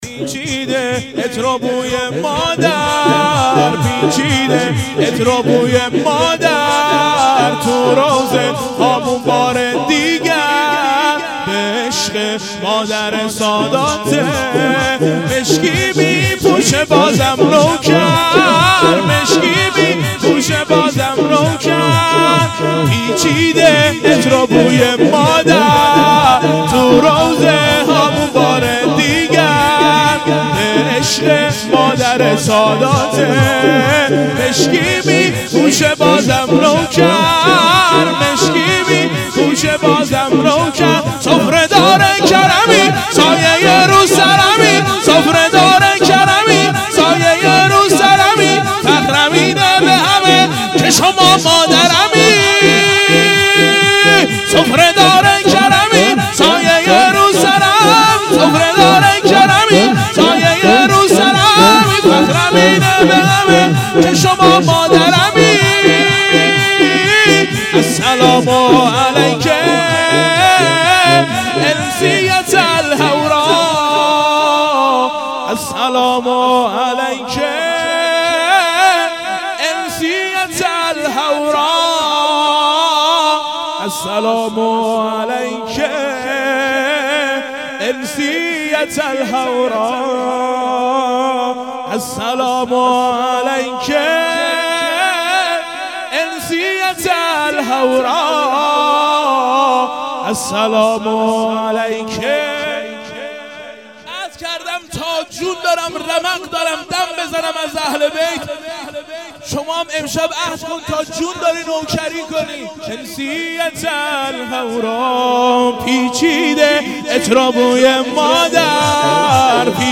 جلسات هفتگی